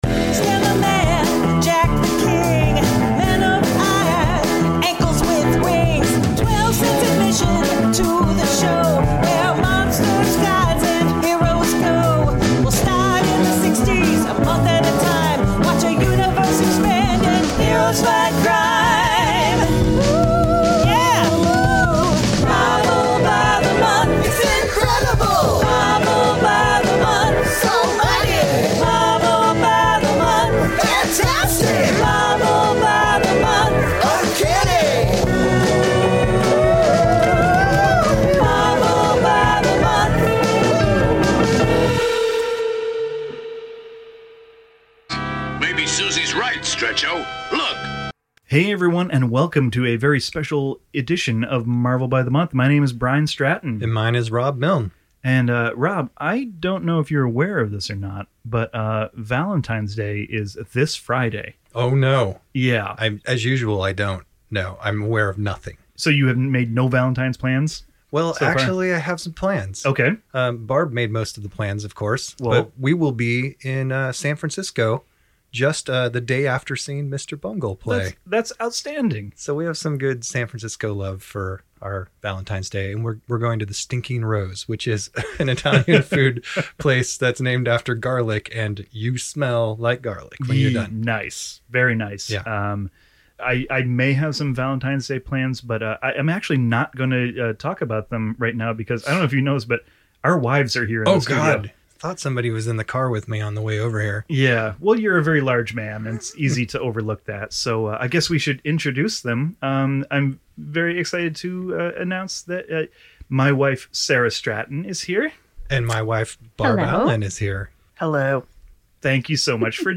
[Borat Voice: My Wiiiiife!!] Re-Reading List: Journey Into Mystery #97, Amazing Spider-Man #9, Fantastic Four #27.